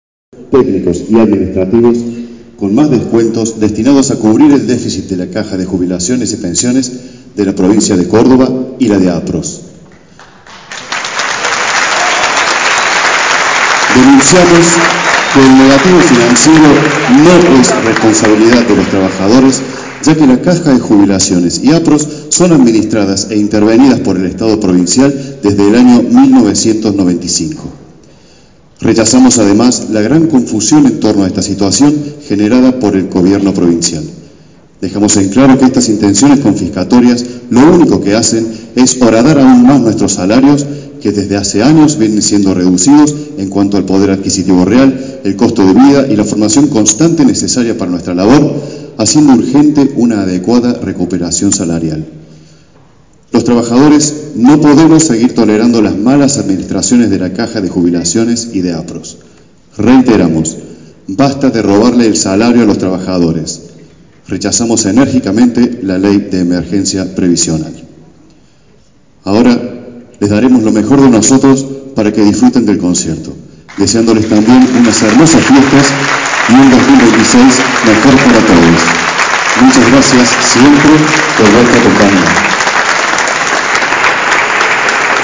En las tres ocasiones el público ovacionó a los músicos  por visibilizar su reclamo. A continuación, reproducimos un tramo del discurso de los músicos.